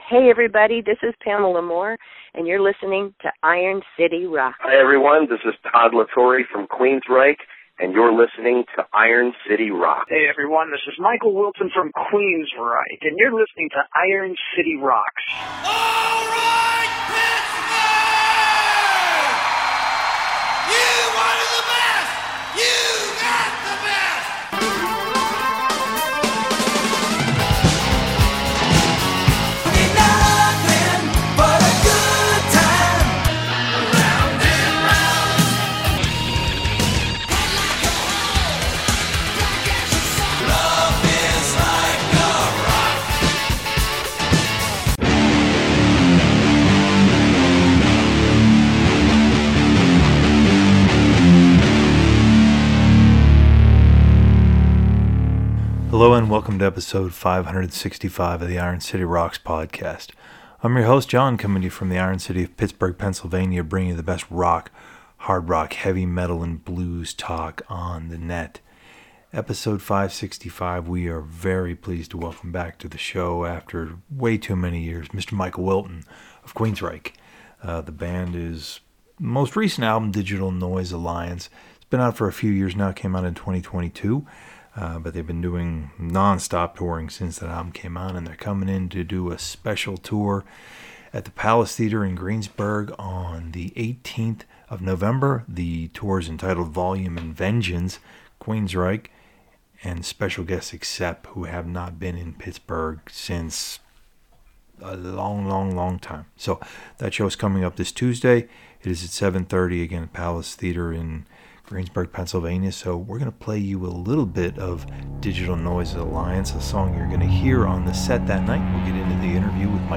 Michael “Whip” Wilton of Queensrÿche joins Episode 565 of the Iron City Rocks Podcast to discuss the Volume and Vengeance tour, his solo work, and new music from the band.
Michael talks with us about their current Volume and Vengeance tour, which features the band touring with Accept. Whip discusses the freedom the band had in choosing the set for this tour, his latest solo album Whip Vol. I, a forthcoming sequel, new music for an upcoming Queensrÿche record, their fans, and much more.